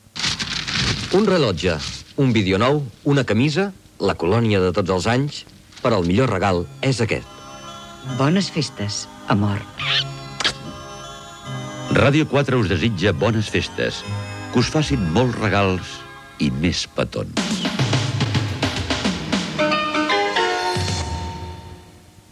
Felicitació nadalenca